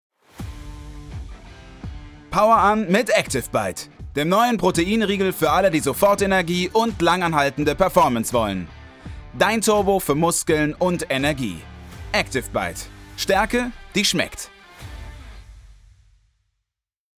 Radiospot für Energieriegel
Meine stimmcharakteristik ist warm, klar und nahbar.